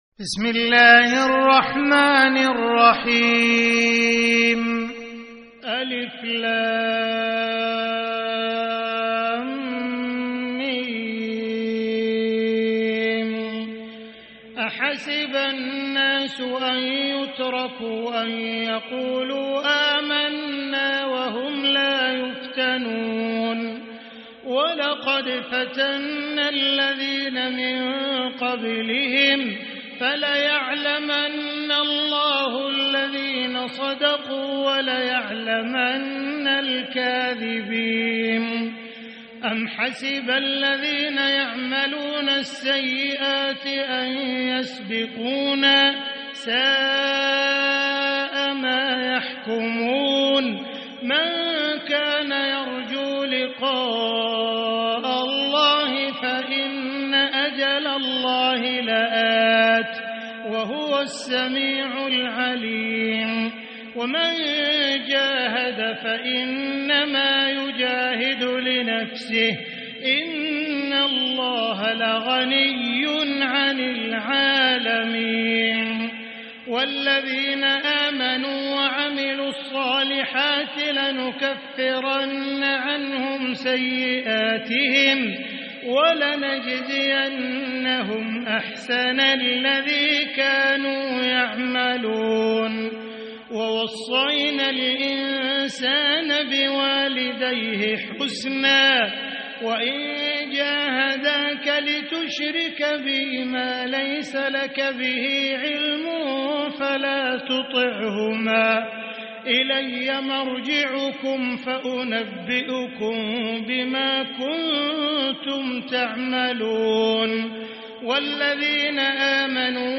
سورة العنكبوت | مصحف الحرم المكي ١٤٤٤ > مصحف تراويح الحرم المكي عام 1444هـ > المصحف - تلاوات الحرمين